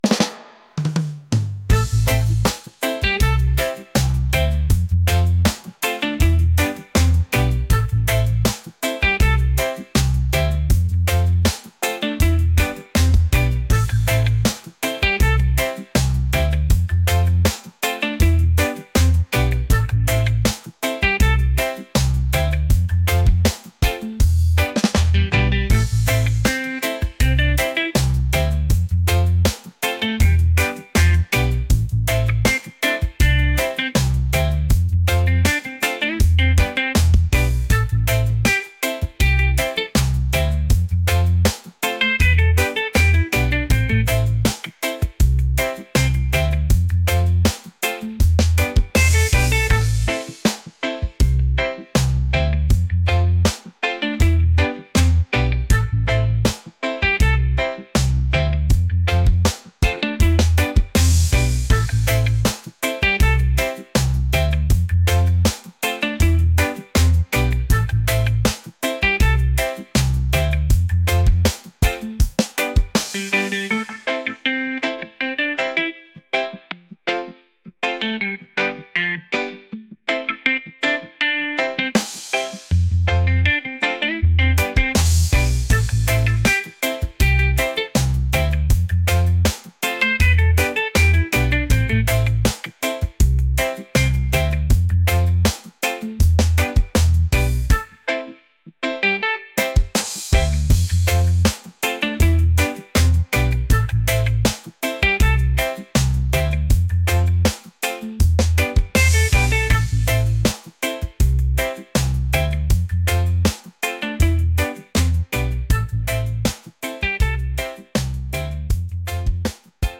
groovy | soulful | reggae